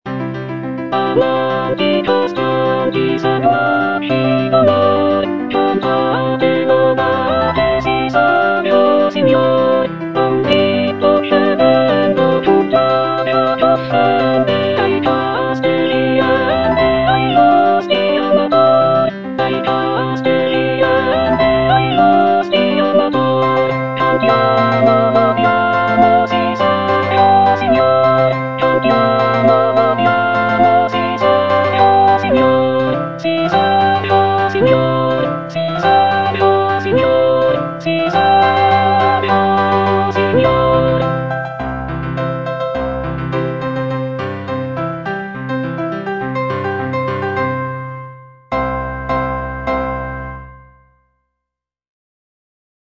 W.A. MOZART - CHOIRS FROM "LE NOZZE DI FIGARO" KV492 Amanti costanti, seguaci d'onor - Soprano (Emphasised voice and other voices) Ads stop: